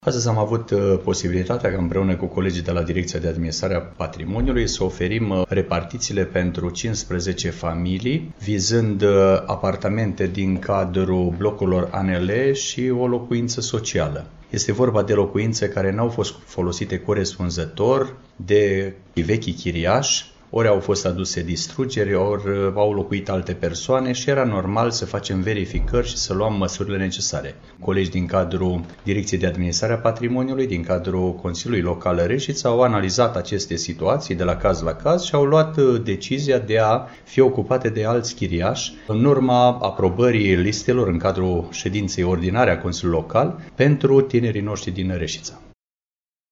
Referitor la aceste repartiţii, edilul Mihai Stepanescu a afirmat: